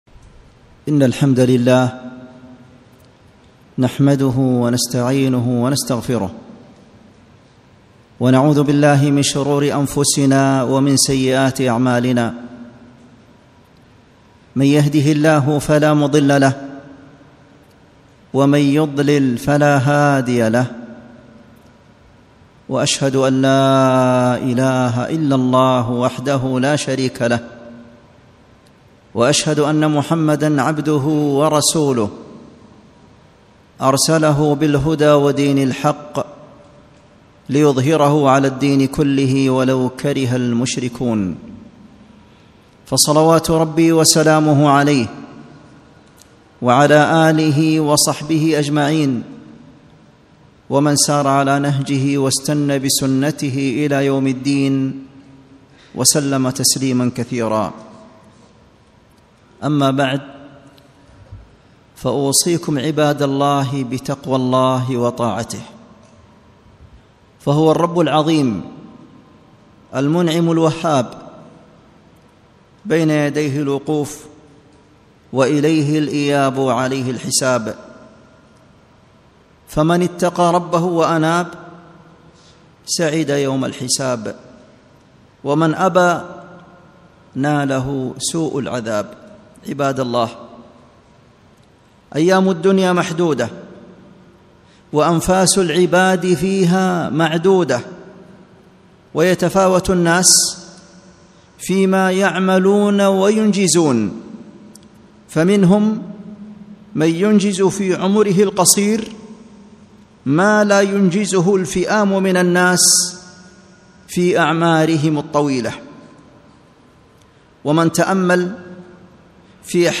خطبة - أسباب تحصيل البركة